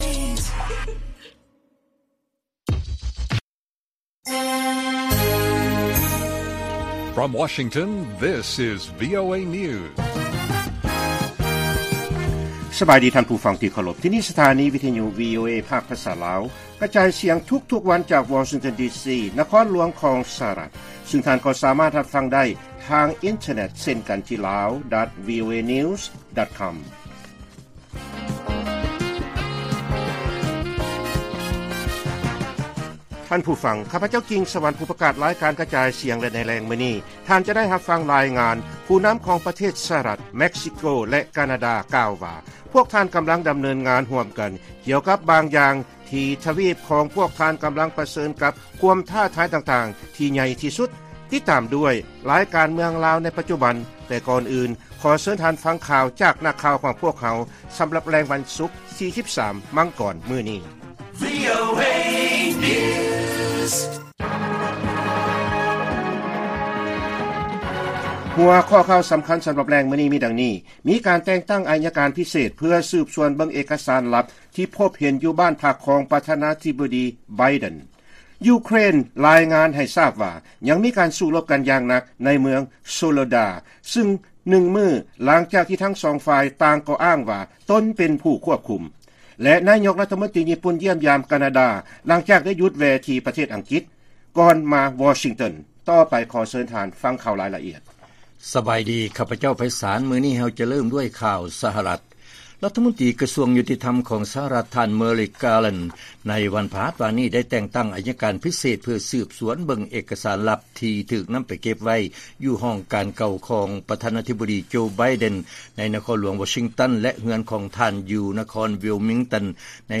ລາຍການກະຈາຍສຽງຂອງວີໂອເອ ລາວ: ມີການແຕ່ງຕັ້ງໄອຍະການພິເສດ ເພື່ອສືບສວນເບິ່ງເອກກະສານລັບທີ່ພົບເຫັນ ຢູ່ບ້ານພັກຂອງປະທານາທິບໍດີໄບເດັນ.